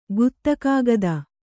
♪ guttakāgada